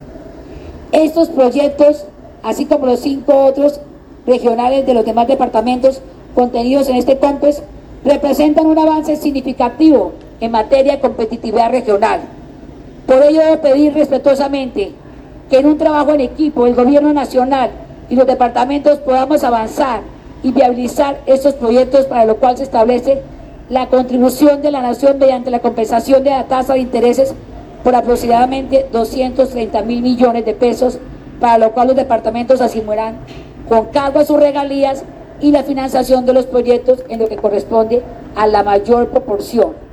Durante la primera Cumbre de Gobernadores de la Orinoquia, realizado en Yopal, Casanare, la gobernadora del Meta, Rafaela Cortés Zambrano, hizo un llamado al gobierno Nacional, para que se adelanten los proyectos comprendidos en el Conpes 4106 de 2022, en el que están incluidos 7 iniciativas de conectividad aérea y terrestre, tres de las cuales son para el Meta.